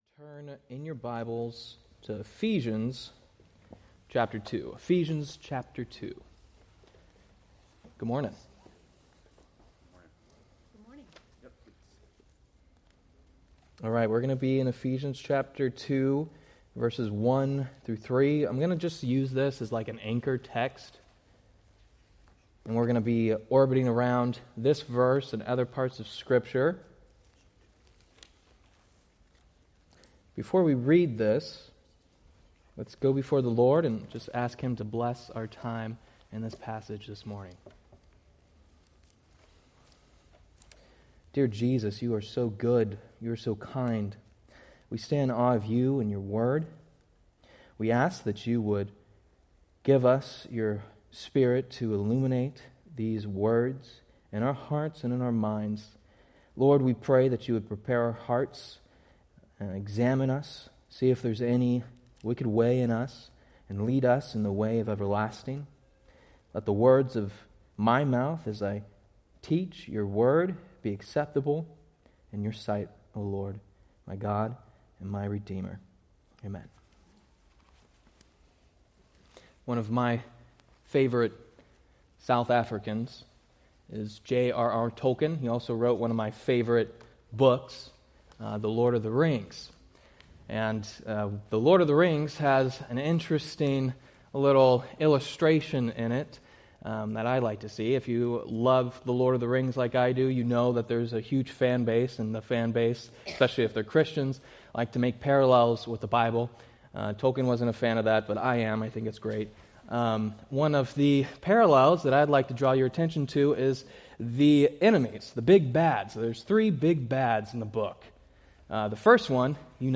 Date: Jan 18, 2026 Series: Various Sunday School Grouping: Sunday School (Adult) More: Download MP3 | YouTube